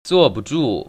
[zuò ‧bu zhù] 쭈어부주